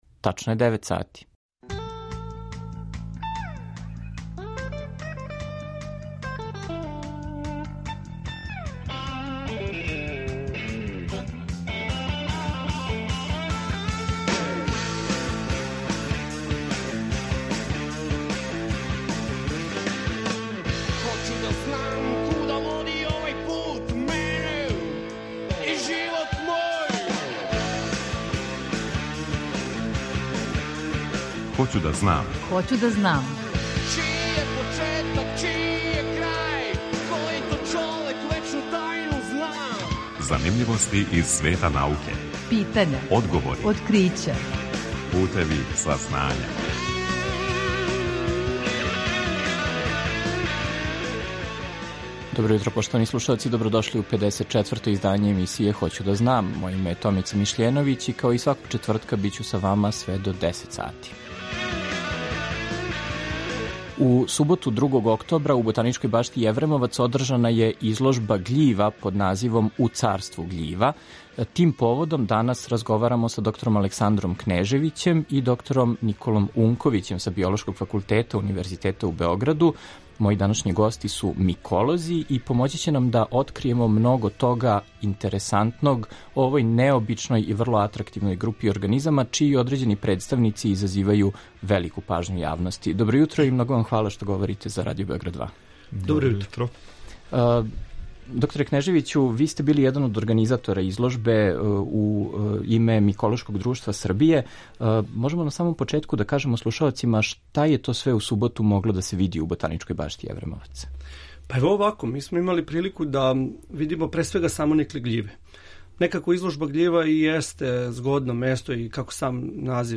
О фасцинантном свету гљива разговарамо